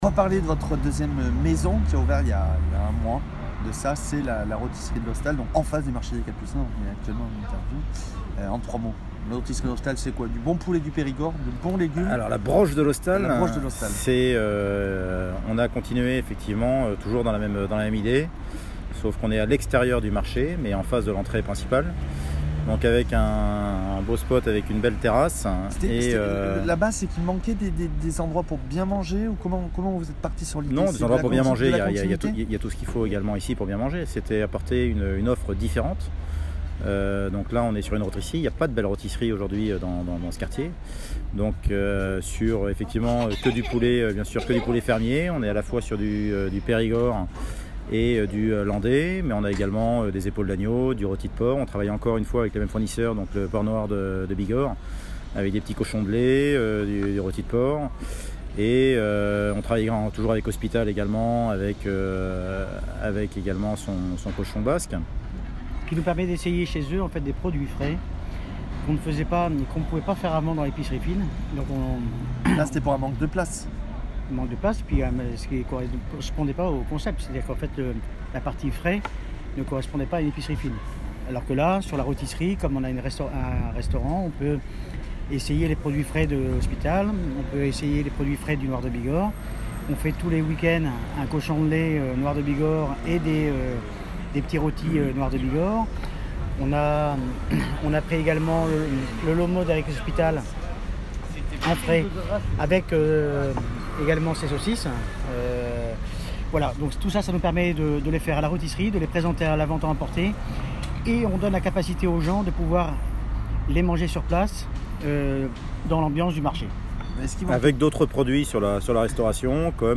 Direction le marché des Capucins pour en savoir plus et découvrir ou redécouvrir le lieu.